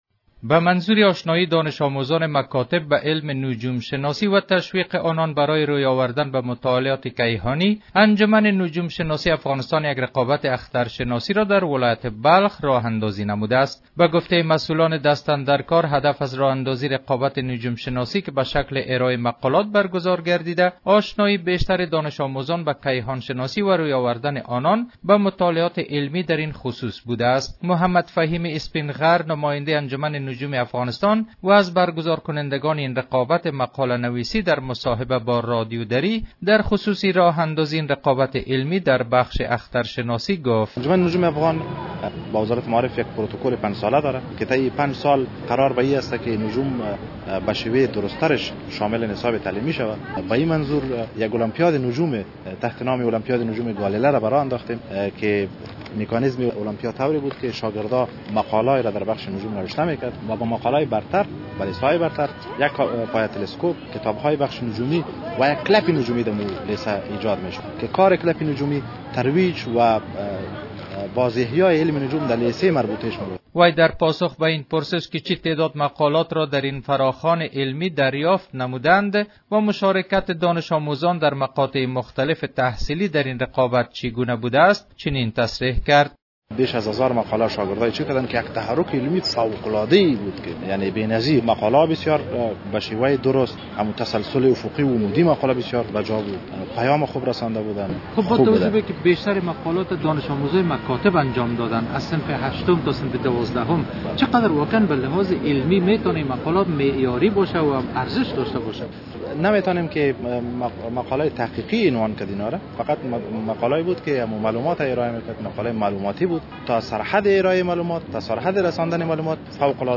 به گزارش خبرنگار رادیو دری،به گفته مسئولان دست اندرکاران هدف ازراه اندازی رقابت نجوم شناسی که به شکل ارایه مقالات برگزار شده است را آشنایی بیشتر دانش آموزان با کیهان شناسی وروی آوردن آنان به مطالعات علمی دراین خصوص عنوان کرد.